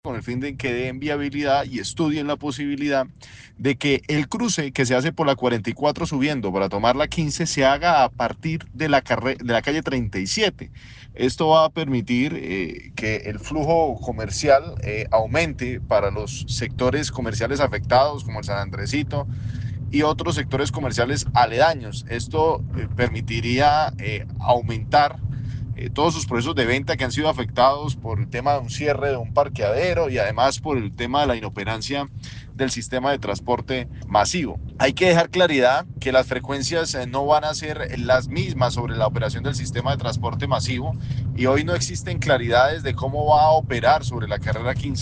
Diego Lozada, concejal de Bucaramanga